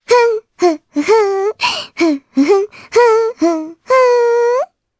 Requina-Vox_Hum_jp.wav